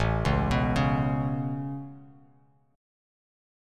Aadd9 Chord